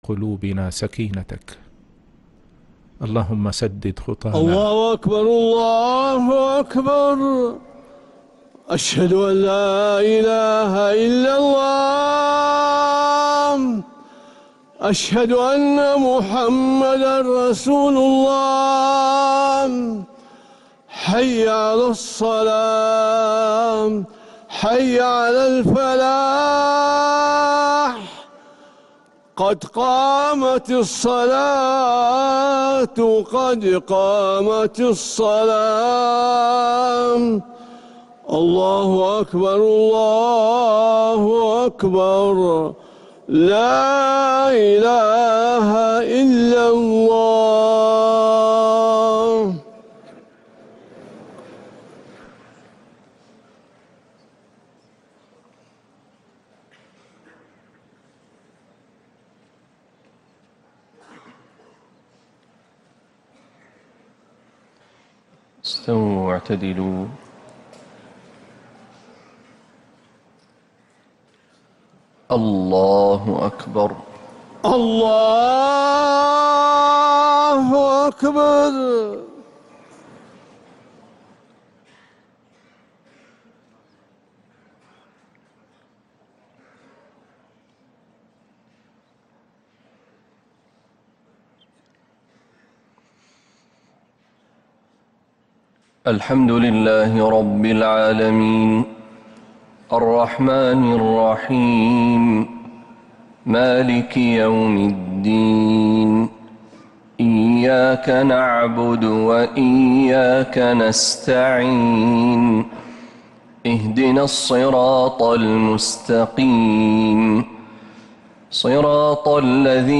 Recordings from Makkah and Madinah